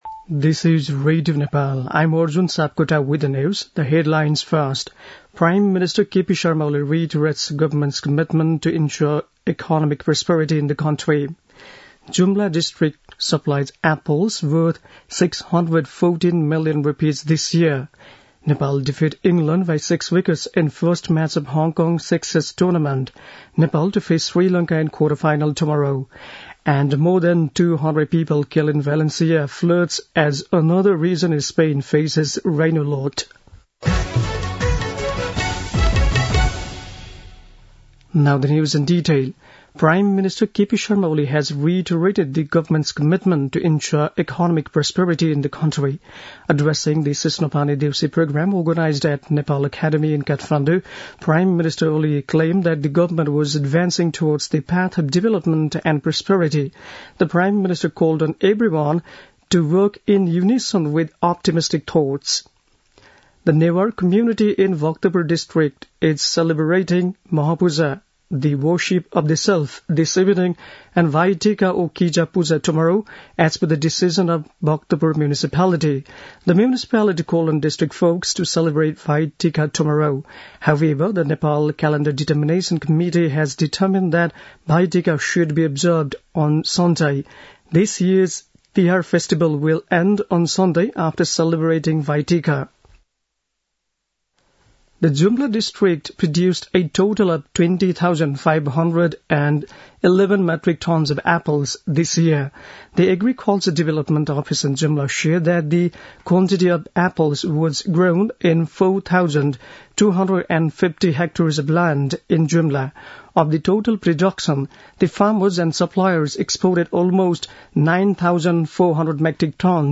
बेलुकी ८ बजेको अङ्ग्रेजी समाचार : १७ कार्तिक , २०८१
8-Pm-english-news-7-16.mp3